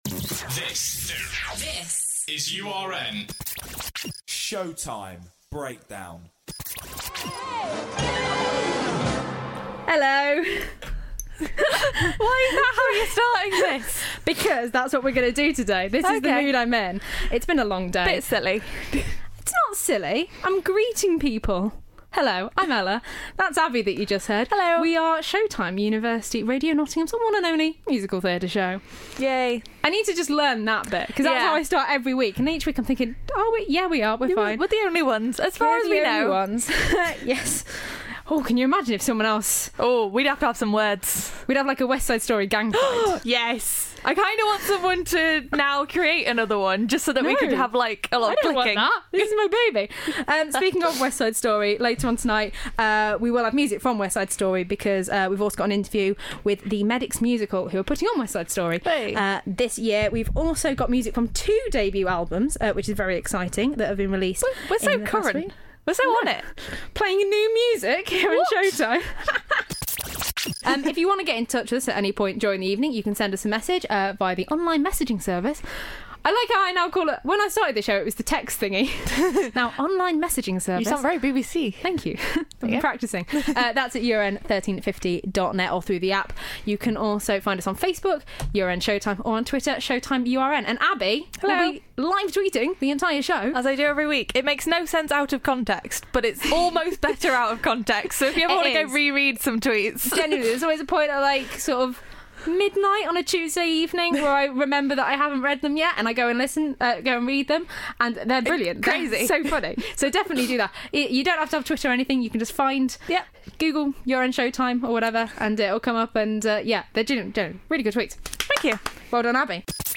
Take a listen for music from two debut albums, an interview with the upcoming Medics' Musical, and a whole host of singing and impressions throughout.